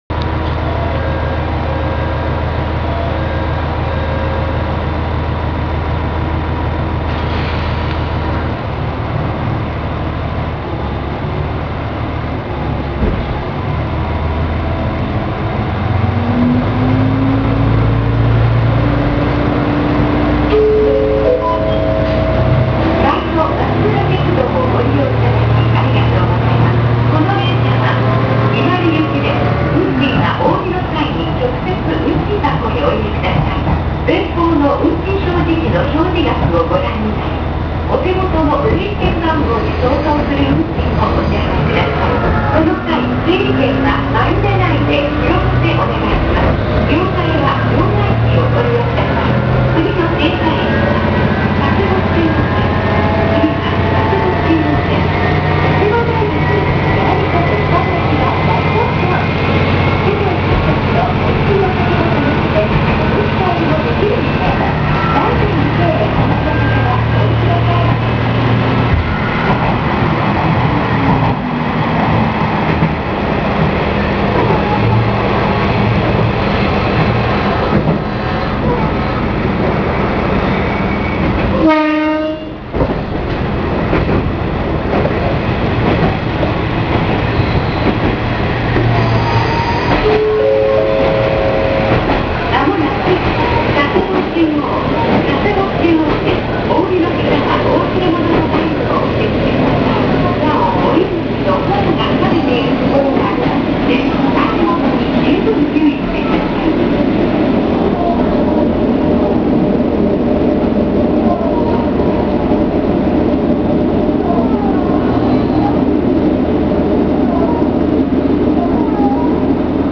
・MR-600形走行音
ごく一般的なディーゼルカーの音。同じドアチャイムは伊勢鉄道等の第３セクターで聞くことができます。日本車両のディーゼルカーの標準的なドアチャイムなのかもしれませんね。
何だか自動放送が古めかしい雰囲気な気がします。